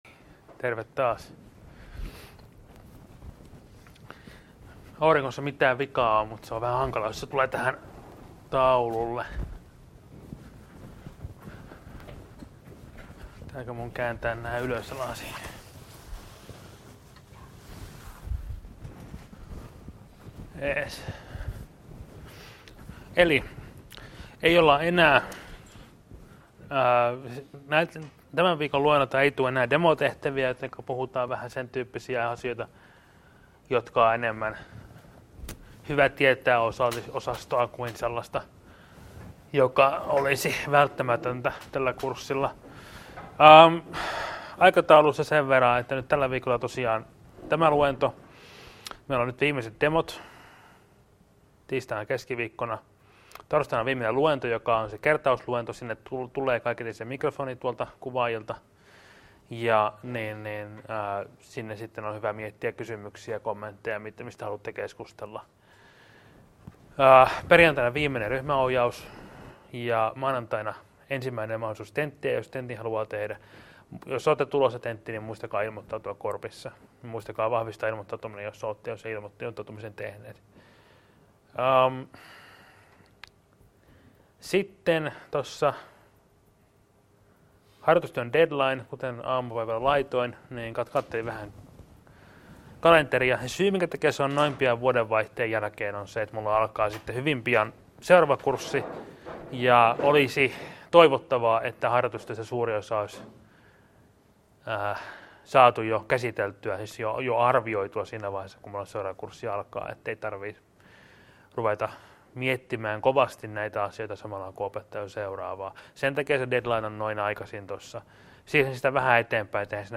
Luento 14.12.2015 — Moniviestin